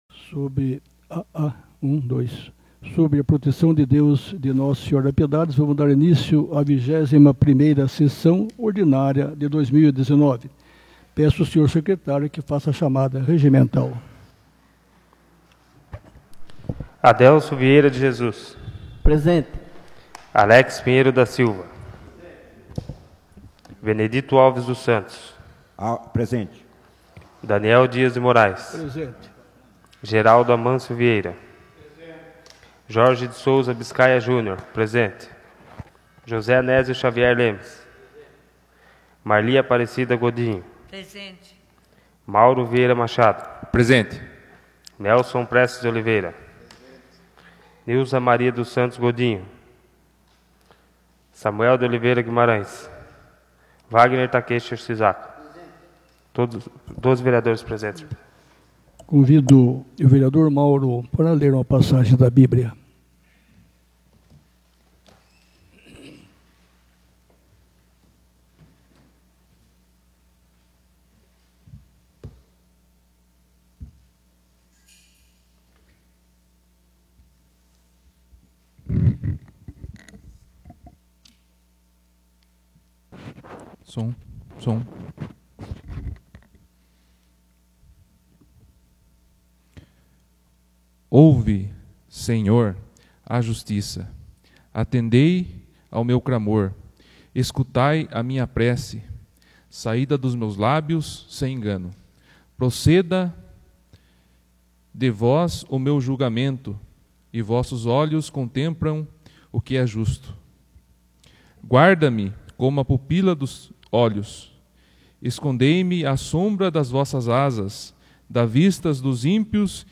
21ª Sessão Ordinária de 2019 — Câmara Municipal de Piedade